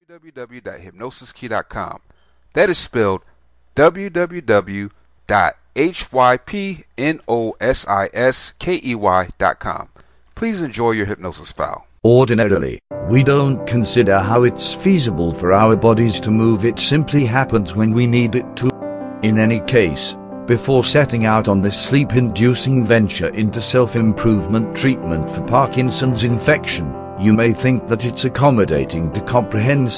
Welcome to Parkinson's Disease Self Hypnosis, this is a hypnosis mp3 that helps relieve the symptoms of Parkinson's Disease.